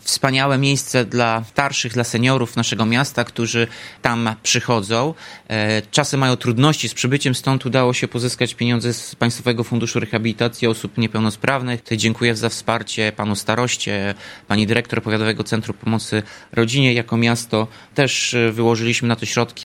Mówi Tomasz Andrukiewicz, prezydent Ełku.